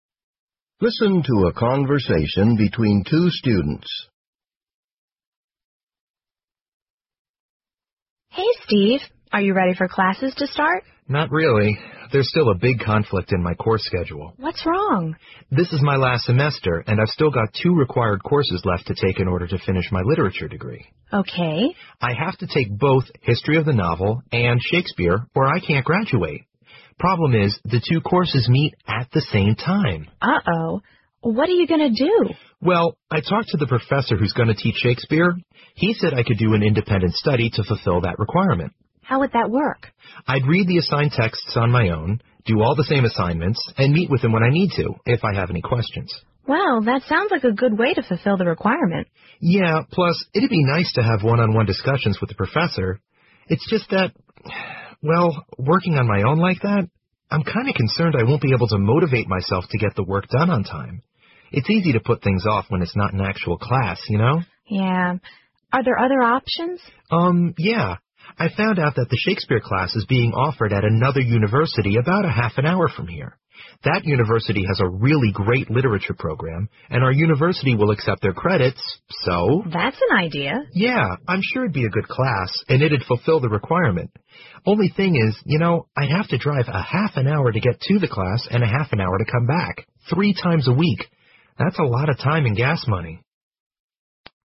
托福口语练习 请听两个学生之间的对话 听力文件下载—在线英语听力室